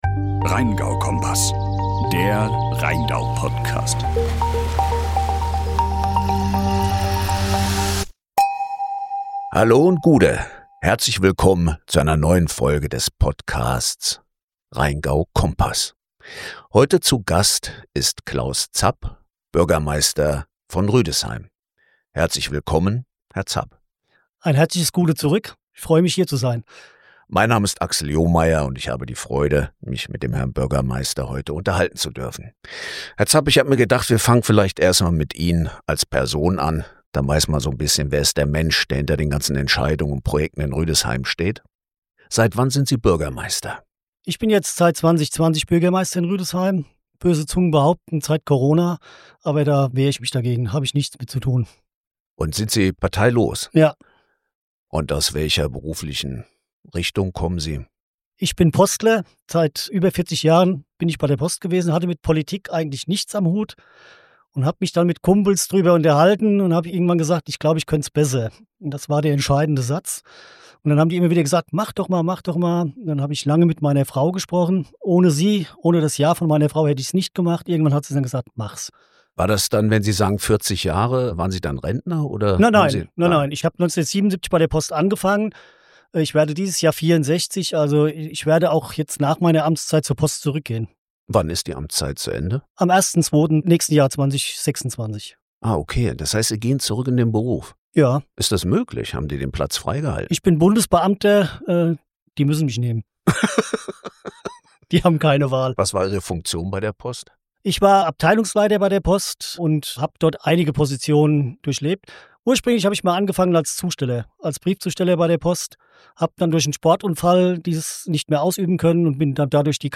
Zapp betont die Bedeutung des Tourismus für Rüdesheim, erklärt laufende Stadtentwicklungsprojekte und äußert sich zur Problematik der Bahnschranke, die eine Lösung in Form eines Tunnels erfordert. Zudem behandelt er das Thema Integration von Flüchtlingen in der Stadt und die Vorbereitungen auf die Bundesgartenschau 2029. Abgerundet wird das Gespräch mit Zapps persönlichen Tipps für Besucher und einem Überblick über die touristische Entwicklung Rüdesheims.